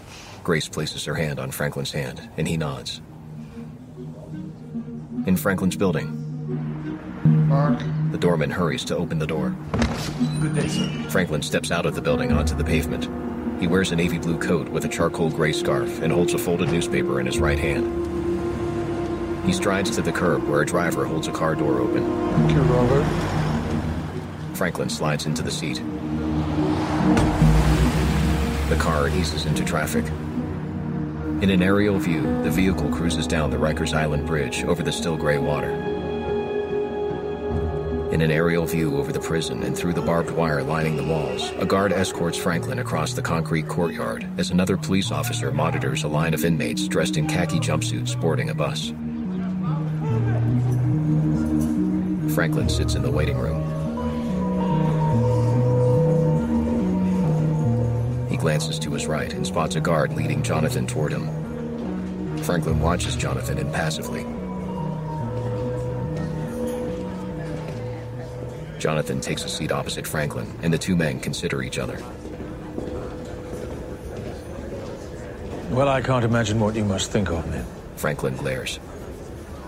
Audio Description, Audio Samples, Clients